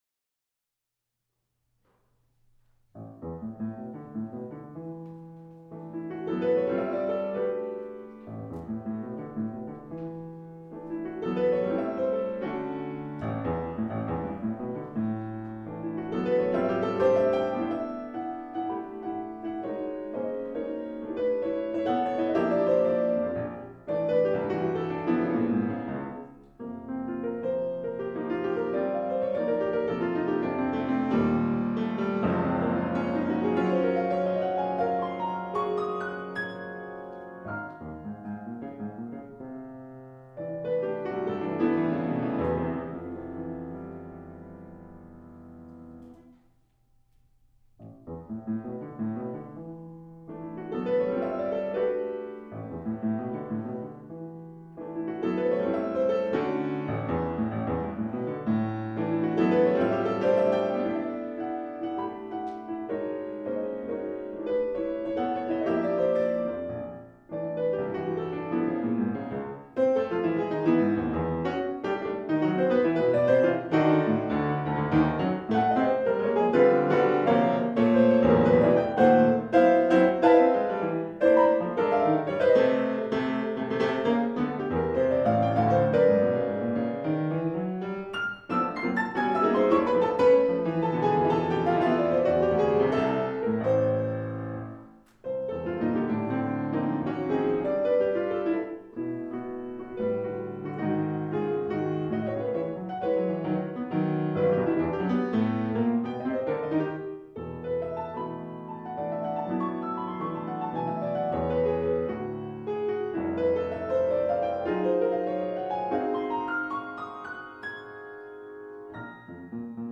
Portland Community Music Center
Piano
II. Adagio rather contemplative and moody all the while slowly builds
III. Allegro con Brio This is the fiery toccata part of the piece which while